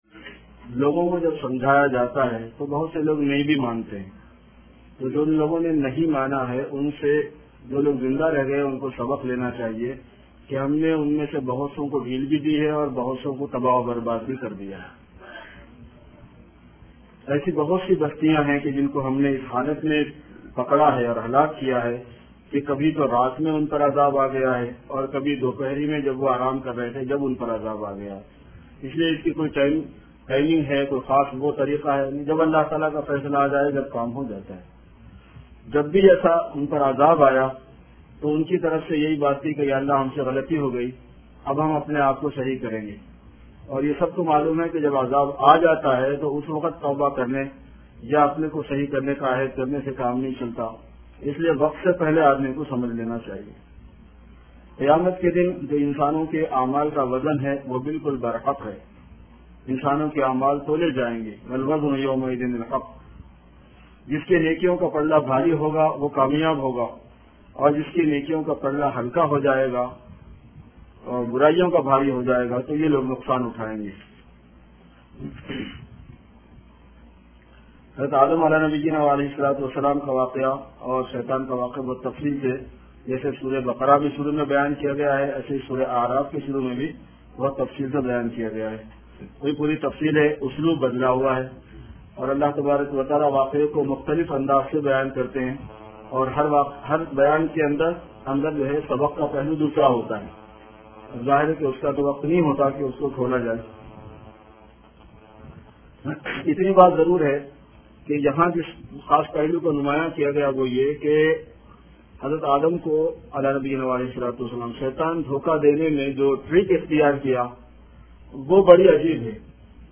After Taraweeh Prayer